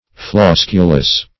flosculous - definition of flosculous - synonyms, pronunciation, spelling from Free Dictionary Search Result for " flosculous" : The Collaborative International Dictionary of English v.0.48: Flosculous \Flos"cu*lous\, a. (Bot.) Consisting of many gamopetalous florets.